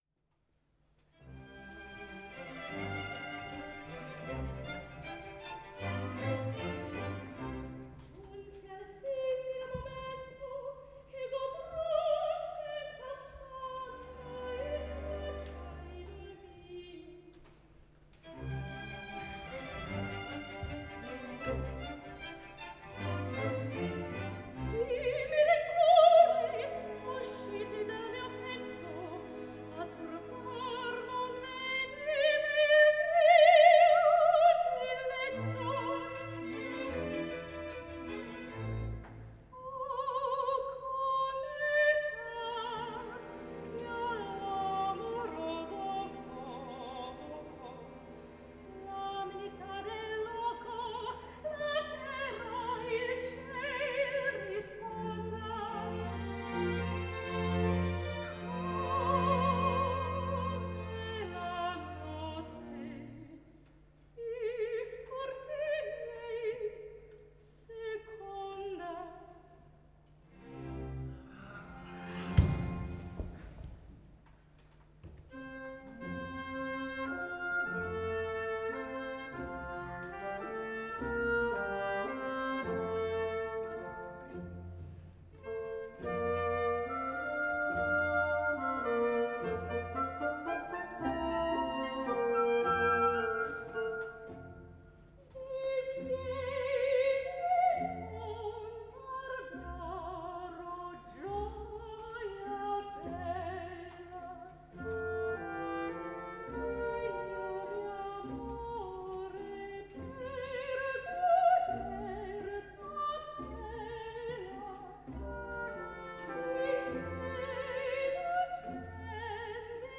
Bei uns lernen Sie Bühnenpräsenz und klassischen Operngesang!
Arie aus dem Figaro und einem Lied von Schubert.